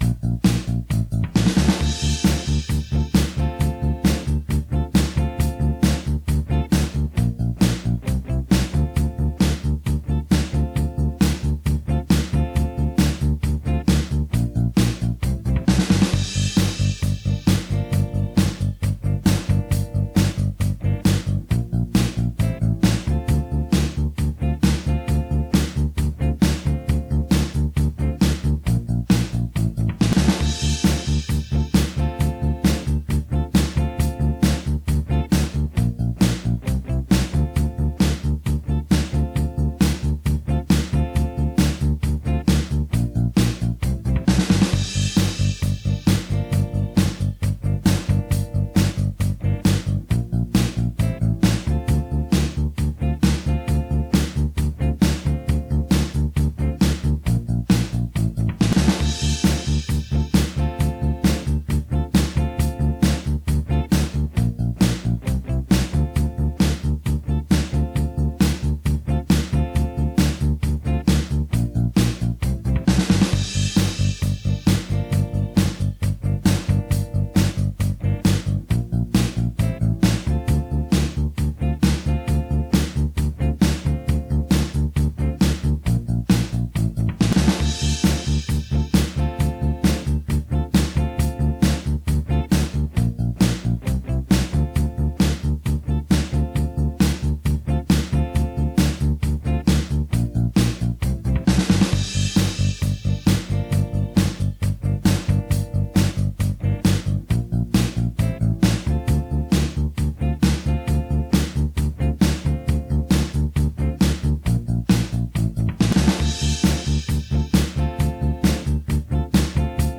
Jak stosować kwarty, aby urozmaicić swoje solówki [PRZYKŁADOWE SOLO]
/ Improwizacja na gitarze / Autor: